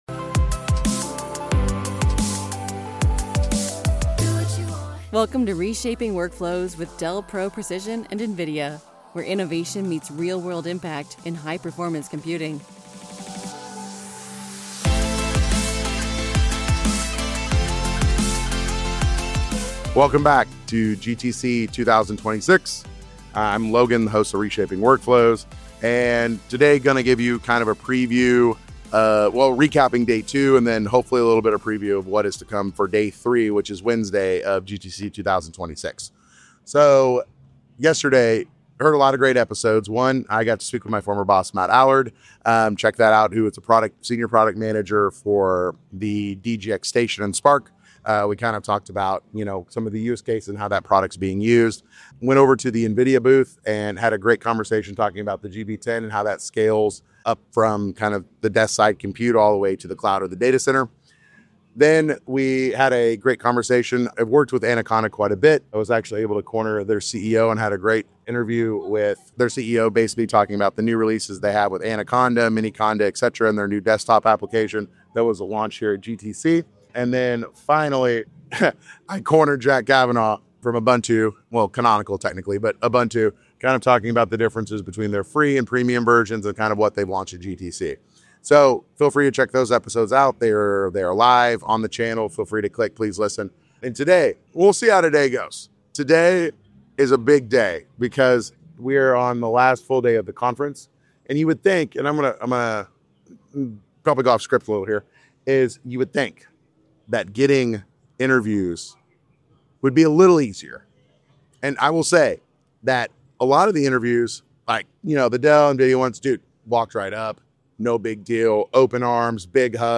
Live from GTC: Day 3 Preview